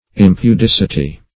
Search Result for " impudicity" : The Collaborative International Dictionary of English v.0.48: Impudicity \Im`pu*dic"i*ty\, n. [L. impudicus immodest; im- not + pudicus shamefaced, modest: cf. F. impudicit['e], L. impudicitia.]
impudicity.mp3